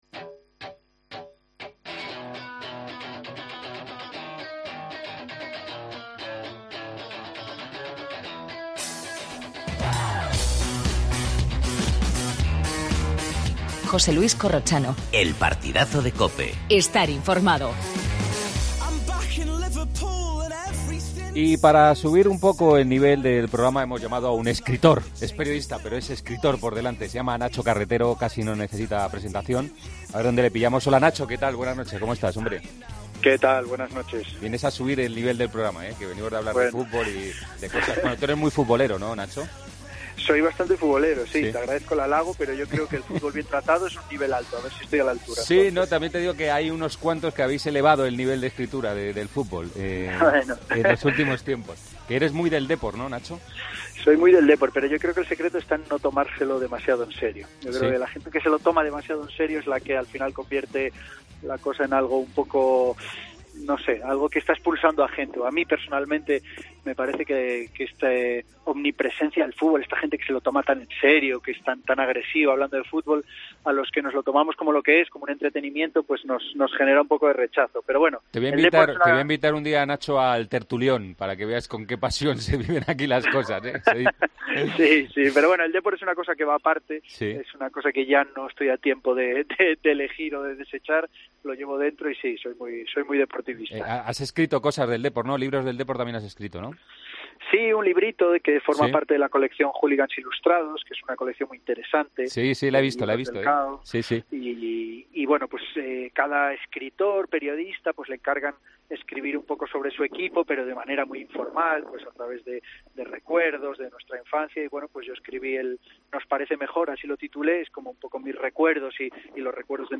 AUDIO: Entrevista al periodista y escritor Nacho Carretero. Agenda del día.